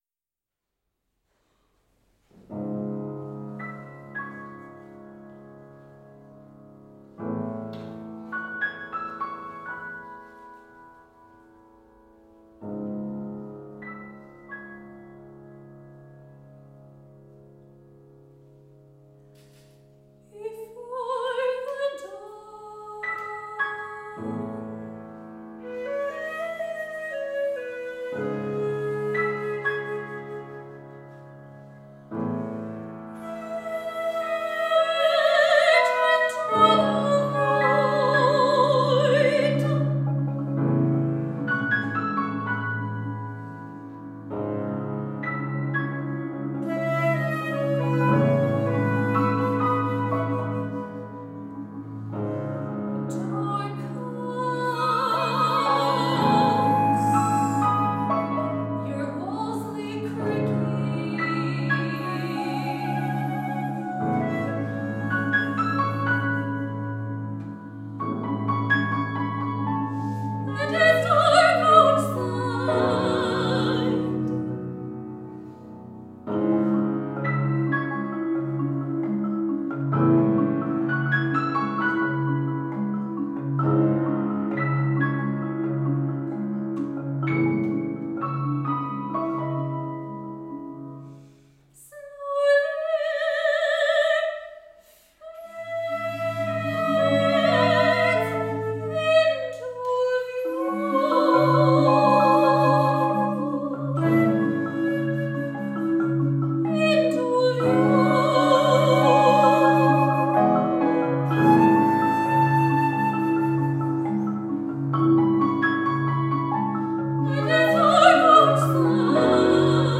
for voice (soprano), flute, marimba, + piano